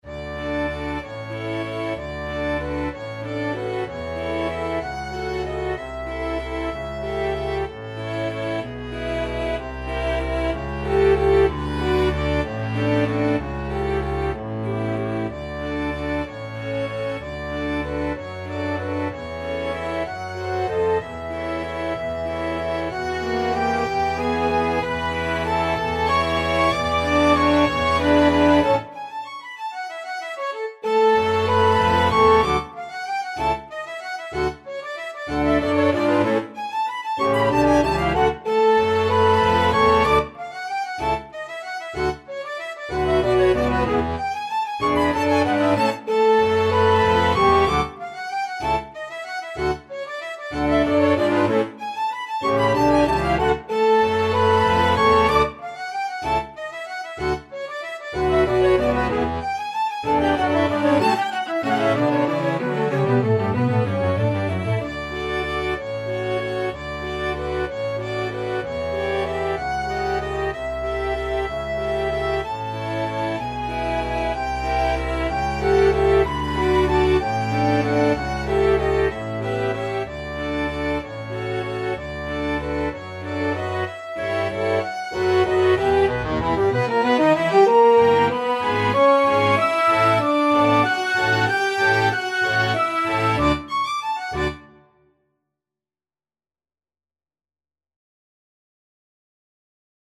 FluteViolin
ClarinetViolin
TrumpetViola
French HornCello
Bass/CelloTrombone
3/4 (View more 3/4 Music)
One in a bar .=c.63